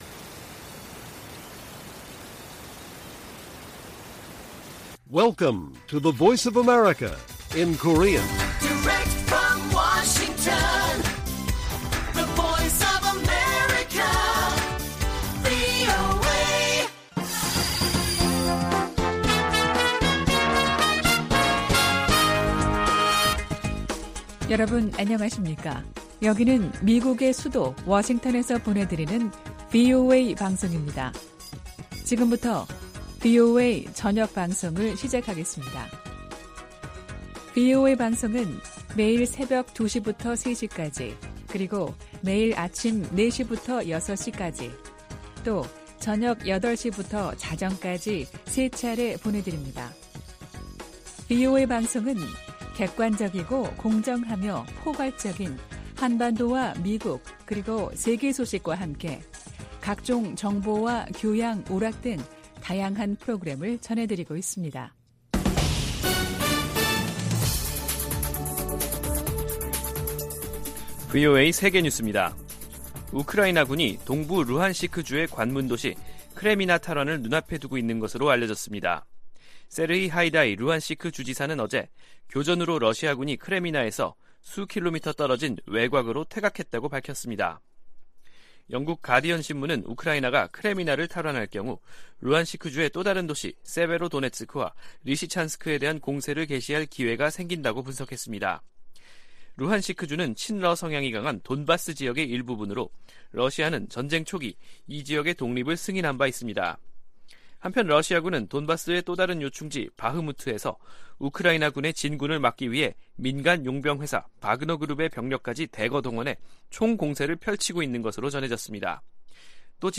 VOA 한국어 간판 뉴스 프로그램 '뉴스 투데이', 2022년 12월 28일 1부 방송입니다. 윤석열 한국 대통령은 북한의 드론 즉 무인기 도발을 계기로 강경 대응 의지를 연일 강조하고 있습니다. 유엔은 북한 무인기가 한국 영공을 침범한 것과 관련해 한반도의 긴장 고조에 대해 우려하고 있다는 기존 입장을 재확인했습니다.